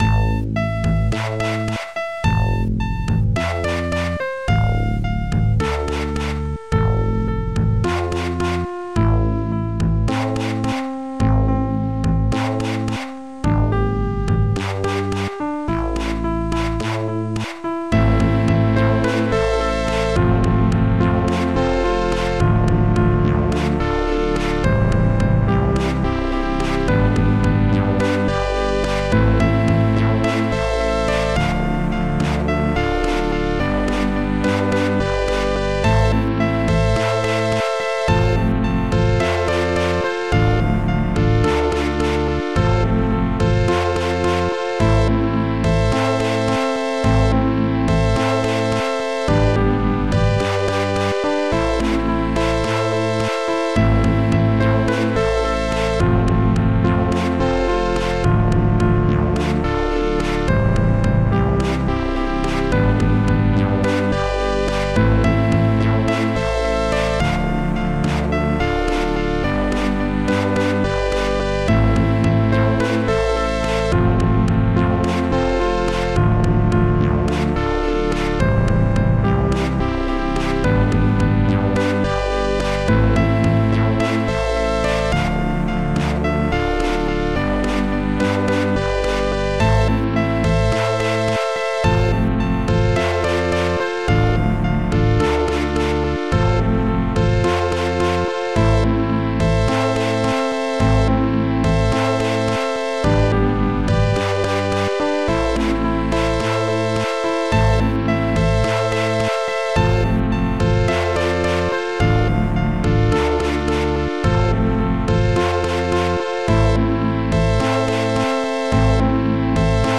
Protracker and family
piano
basssynth
akkord-dur
akkord-moll
Acusticbass2